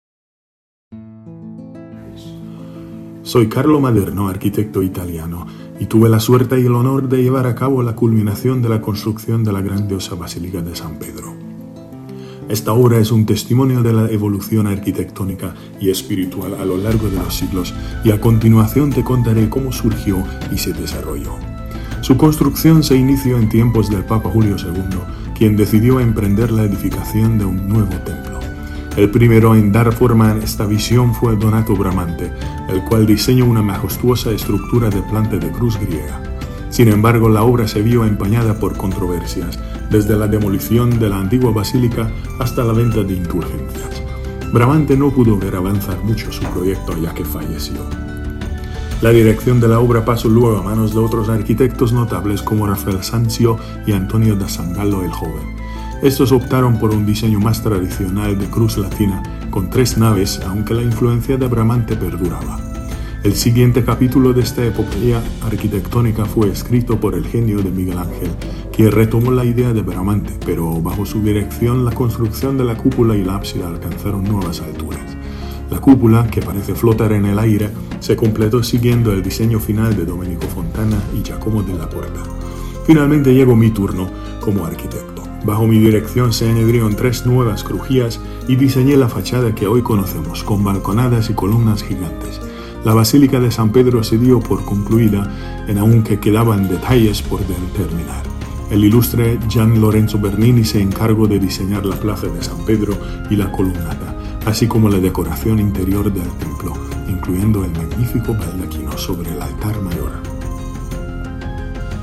Maderno-with-music.mp3